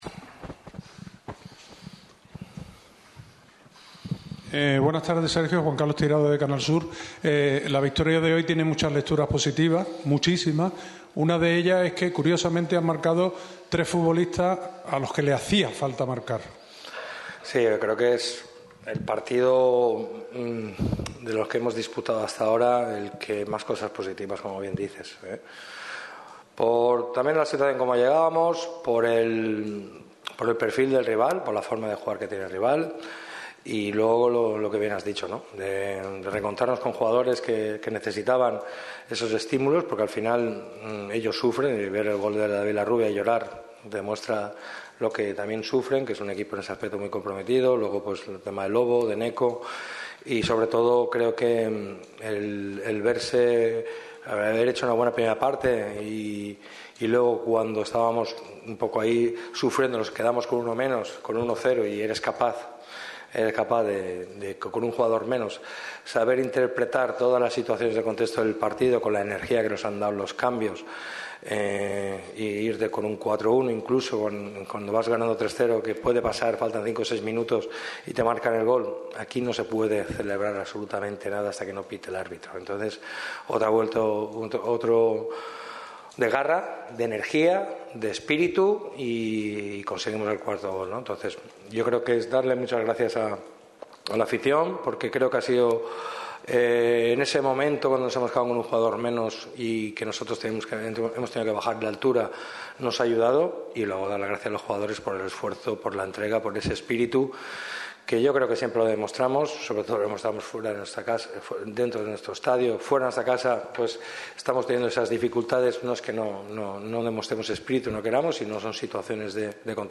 El técnico malaguista ha comparecido ante los medios al término del duelo que enfrentaba a los boquerones contra el Andorra.